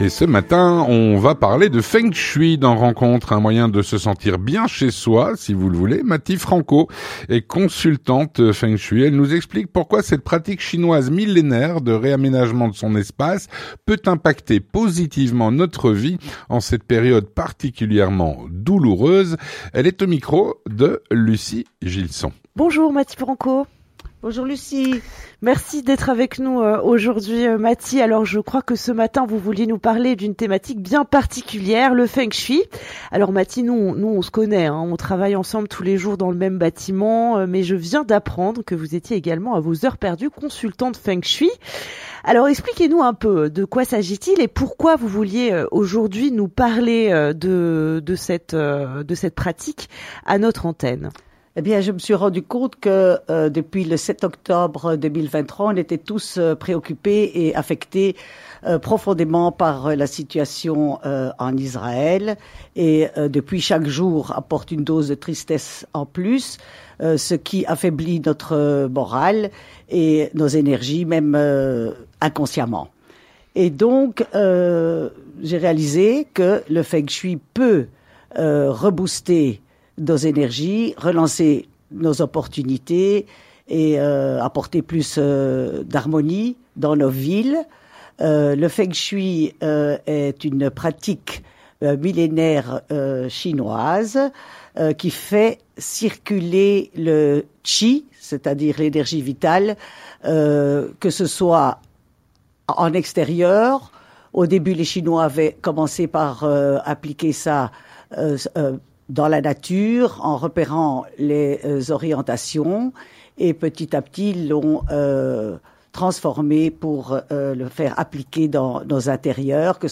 Rencontre - Le Feng Shui : un moyen de se sentir bien chez soi.